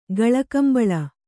♪ gaḷakambaḷa